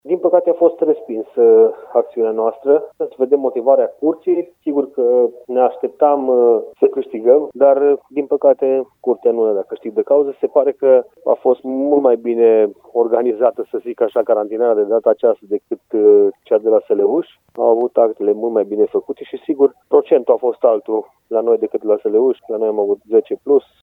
Primarul Călin Abrudan spune că așteaptă să primească motivarea pentru a vedea cum vor proceda în continuare.